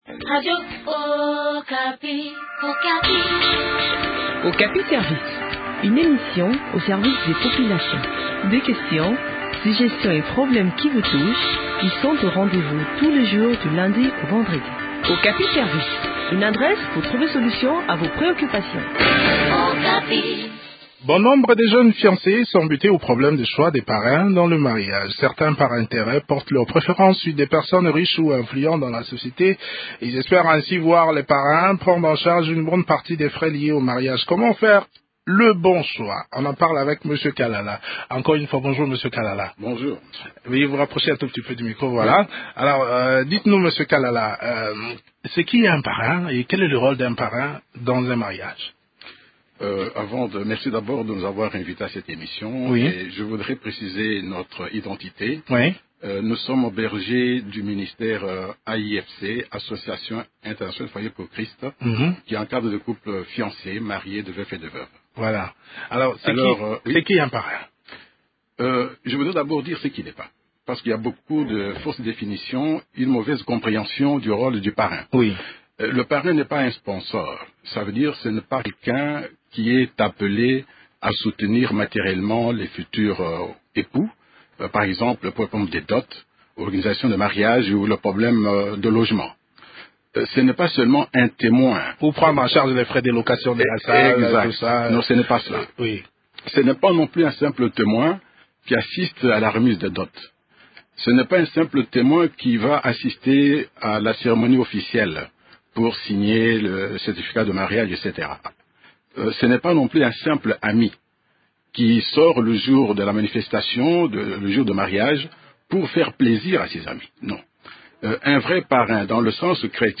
Il donne quelques conseils dans cet entretient